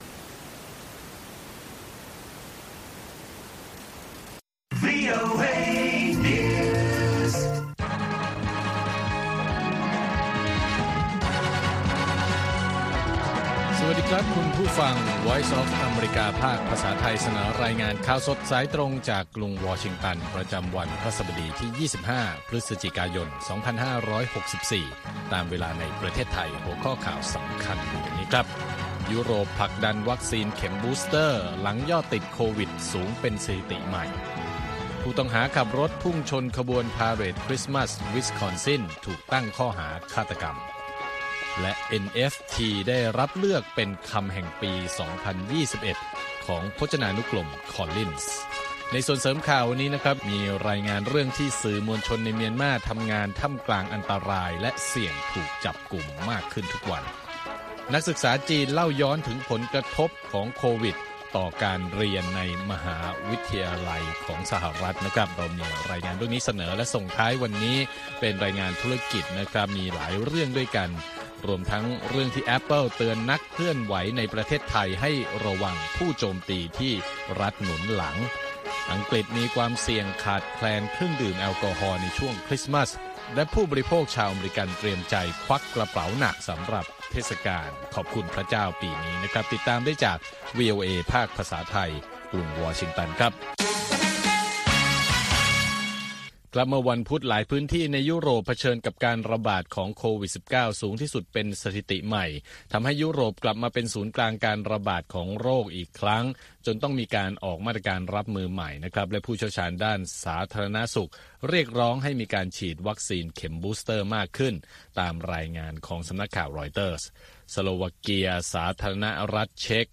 ข่าวสดสายตรงจากวีโอเอ ภาคภาษาไทย 6:30 – 7:00 น. ประจำวันพฤหัสบดีที่ 25 พฤศจิกายน 2564 ตามเวลาในประเทศไทย